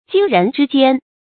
金人之缄 jīn rén zhī jiān
金人之缄发音